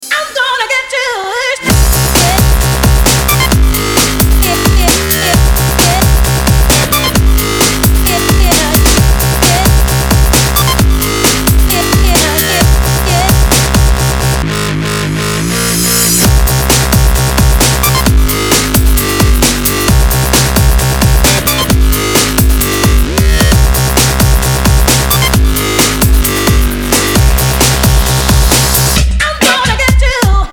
громкие
Electronic
drum n bass
Breaks
Стиль: breaks, drum&bass